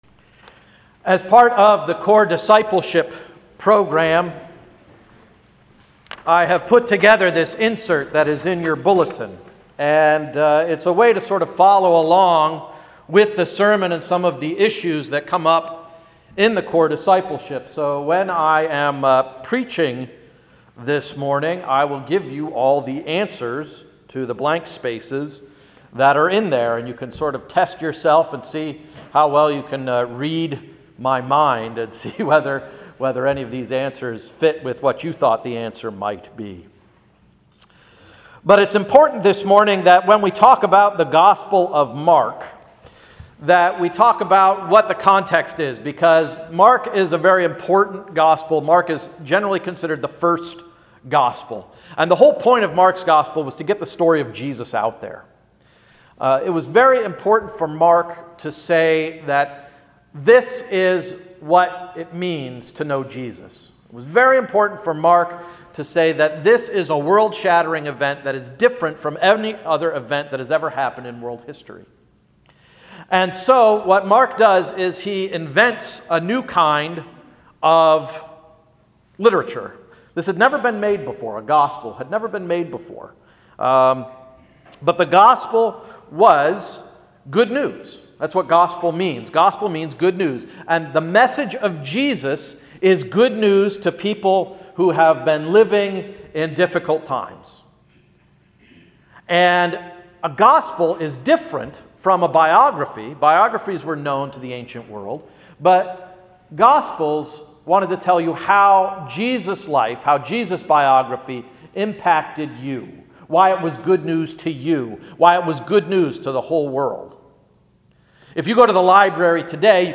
Sermon of January 25, 2014–“Shaped by Christ”